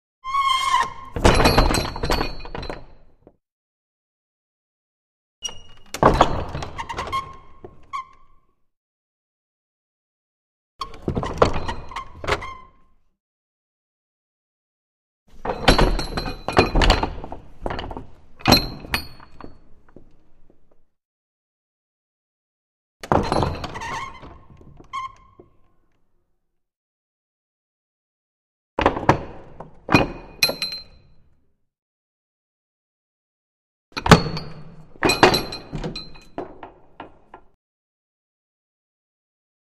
Large Wooden Main Gate 2; Open And Close, Exterior, With Metal Bolt, Rattle On Closes